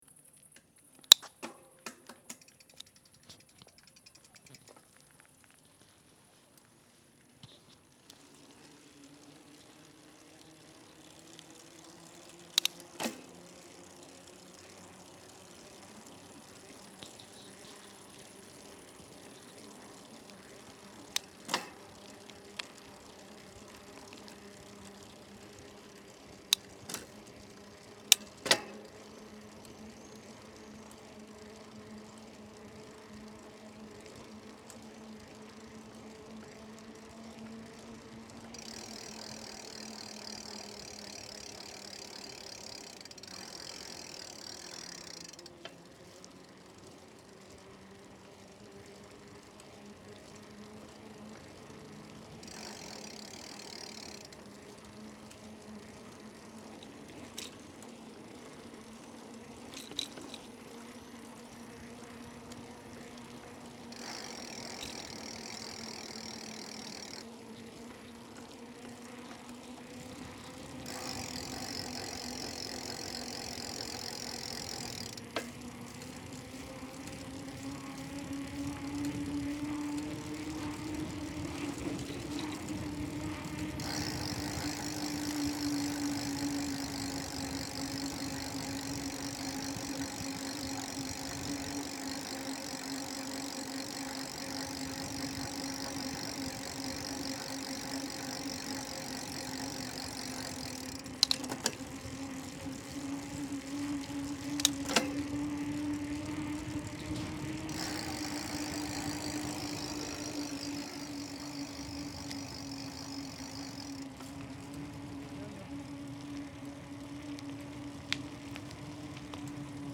13. Велосипед — езда по велодорожке на горном велосипеде, переключение передач, шум шипованных шин, остановка с легким торможением
velosiped-ezda.mp3